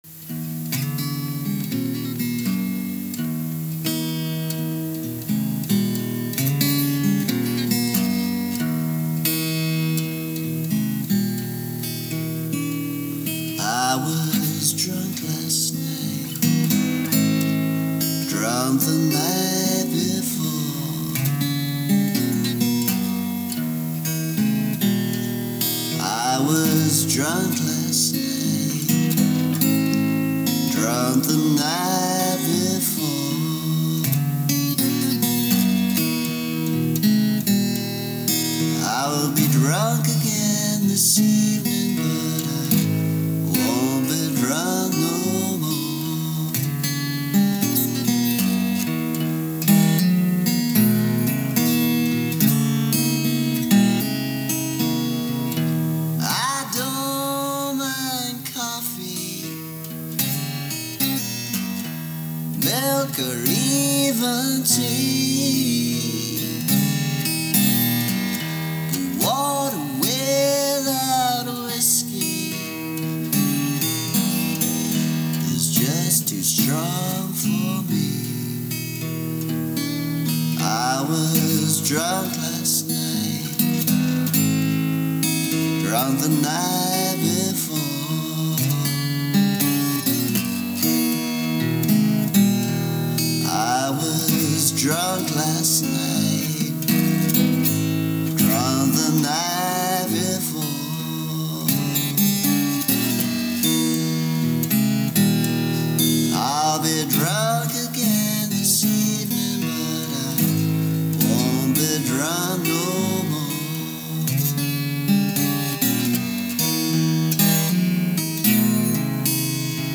Old version, but in reasonable shape considering it fell off a cassette: